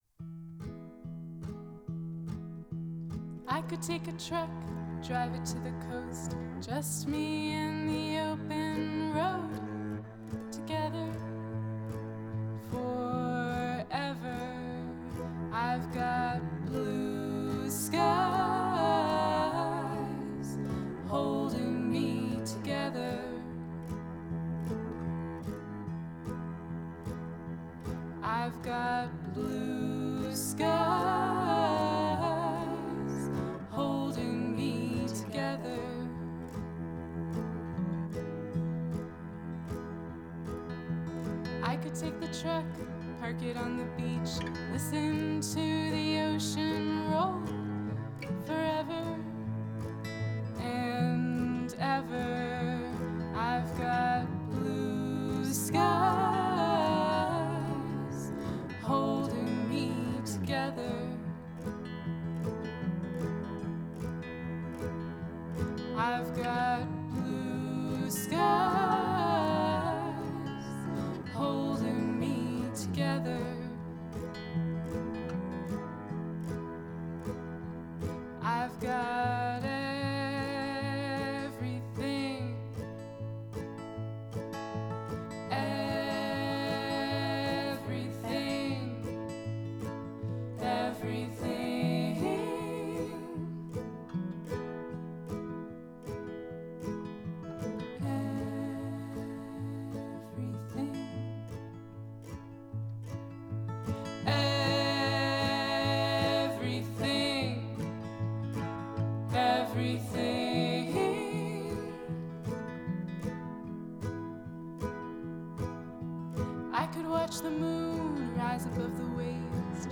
Blue Skies 5-piece all-female acoustic country band. Cello, mandolin, guitars and 4-part harmonies. https